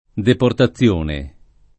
[ deporta ZZL1 ne ]